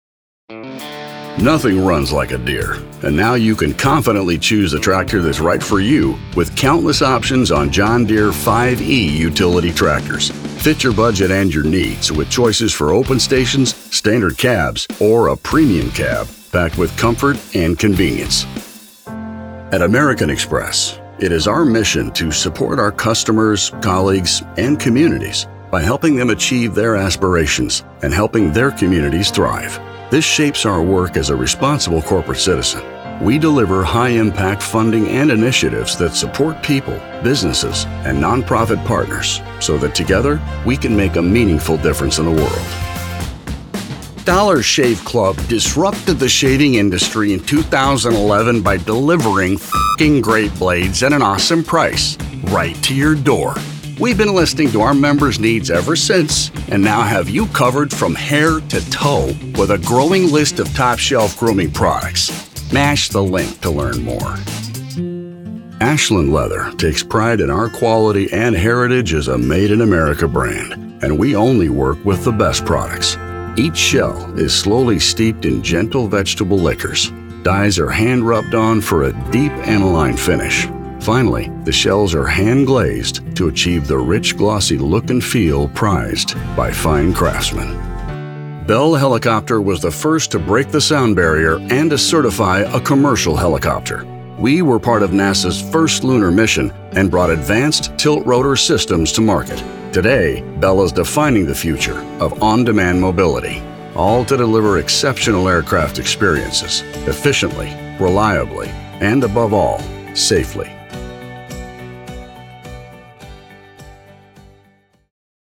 Male
English (North American)
Adult (30-50), Older Sound (50+)
Corporate
0506Corporate_Demo.mp3